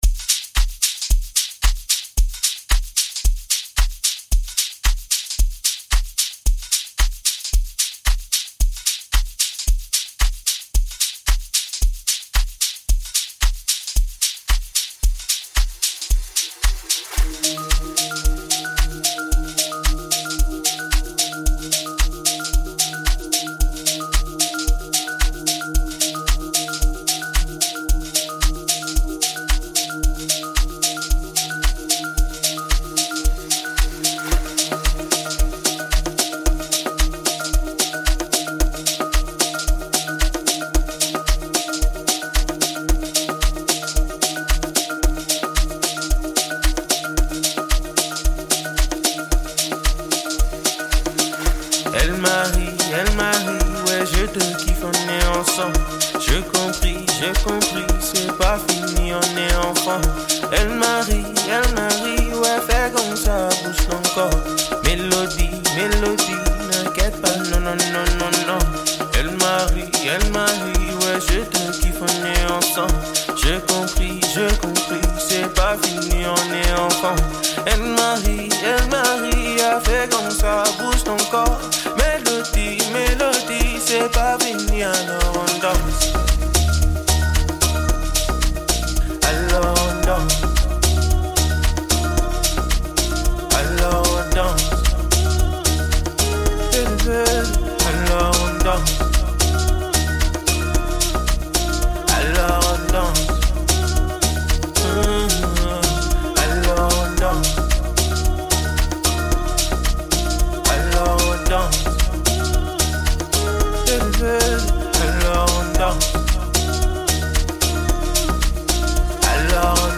and included guitar in the First-Class mix masterfully.